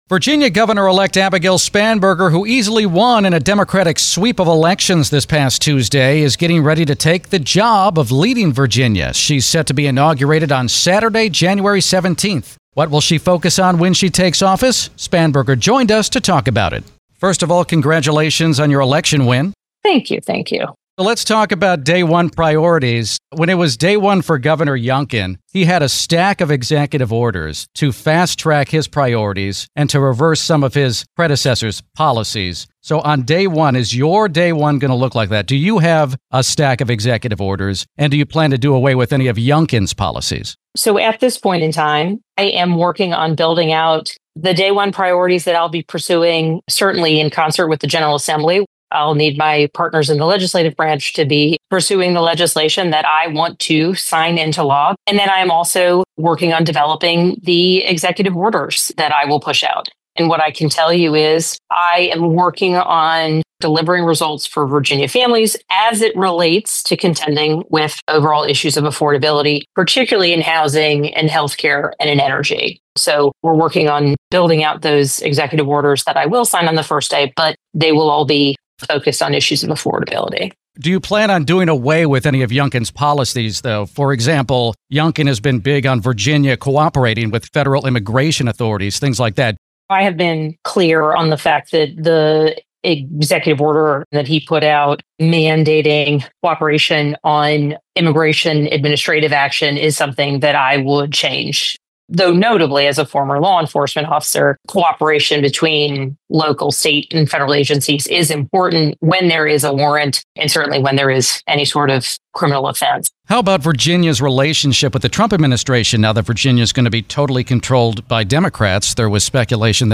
The audio below has been edited for broadcast on WTOP.